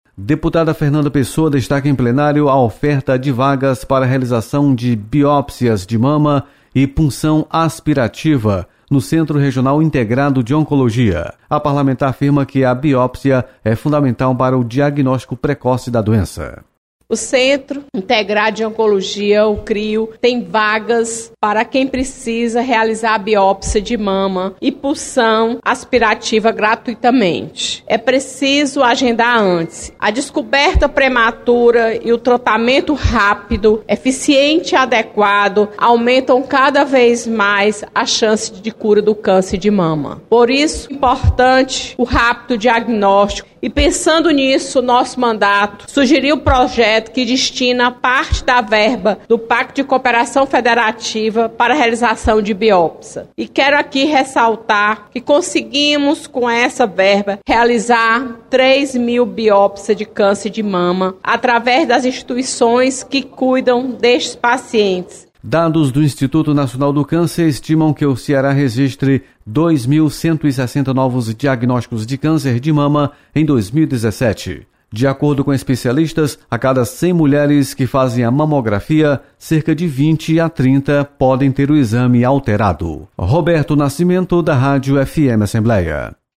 Deputado Fernanda Pessoa destaca ampliação de biópsias no Estado. Repórter